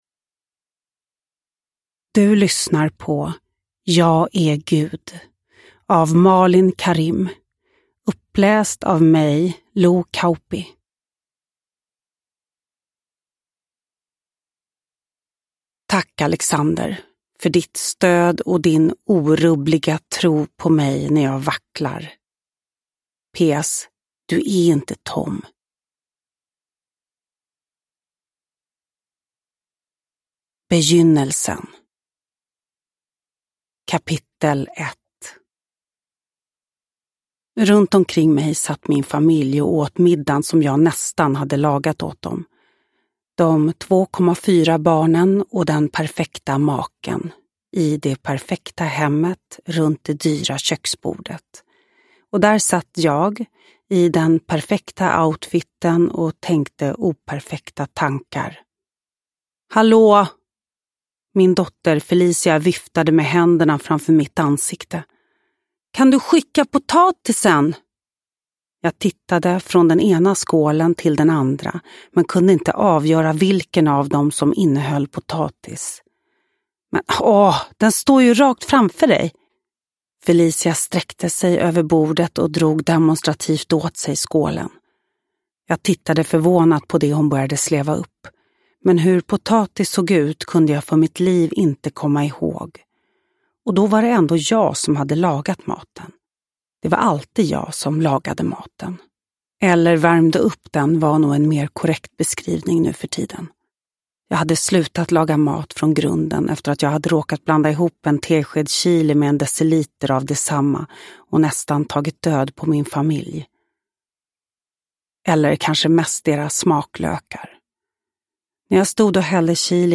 Uppläsare: Lo Kauppi